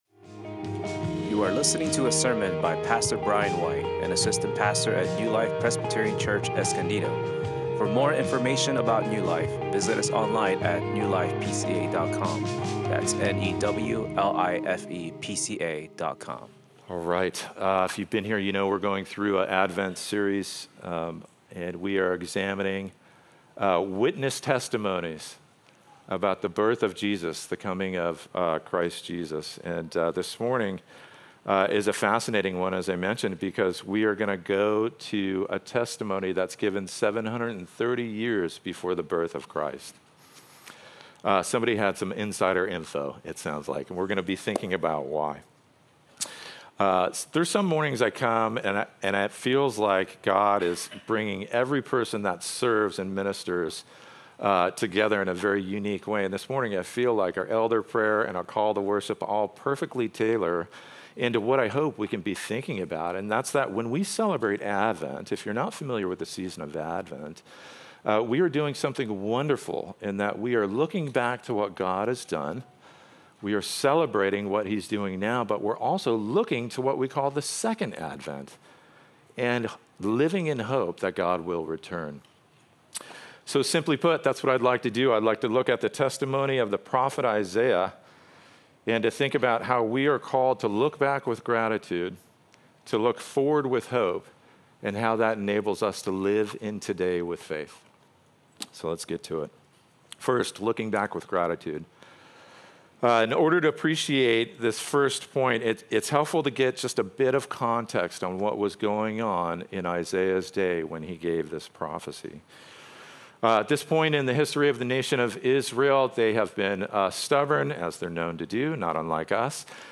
Recent sermons preached at New Life Presbyterian Church